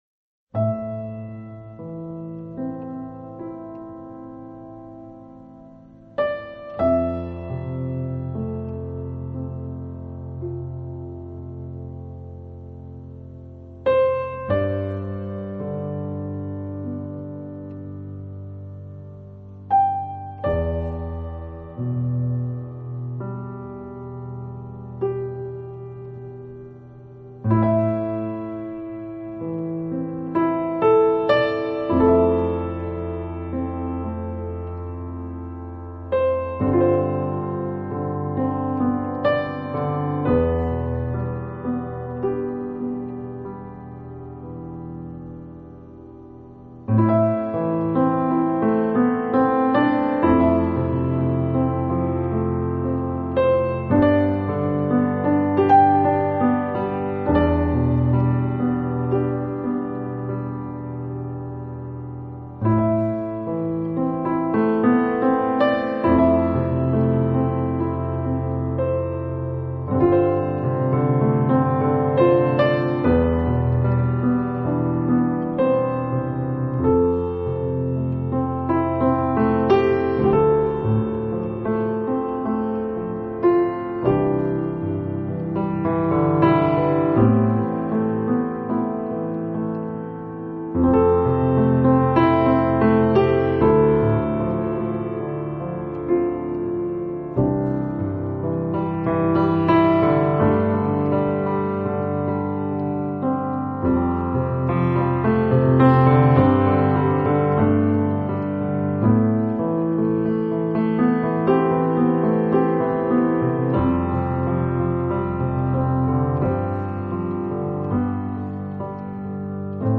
音乐类型：NewAge
斯——他演奏锡口笛、双簧管、英国管和高音萨克斯风部分——